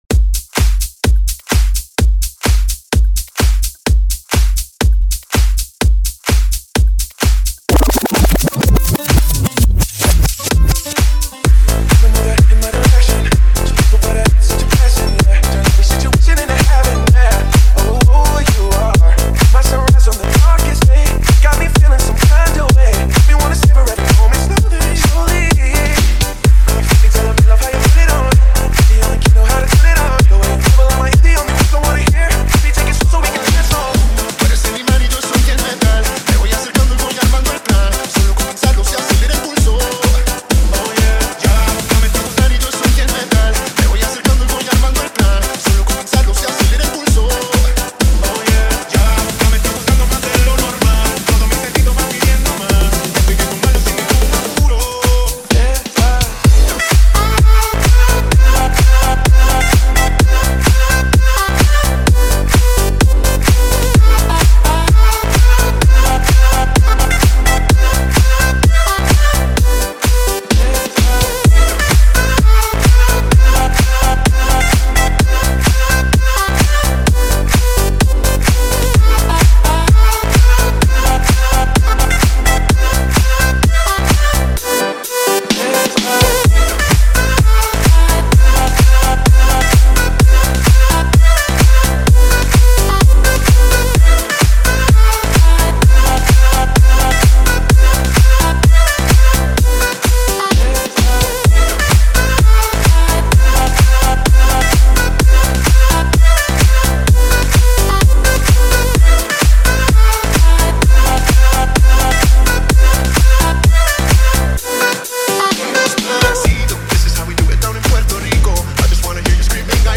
دنس پاپ لاتین remix Latin Dance pop